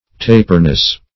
Search Result for " taperness" : The Collaborative International Dictionary of English v.0.48: Taperness \Ta"per*ness\, n. The quality or state of being taper; tapering form; taper.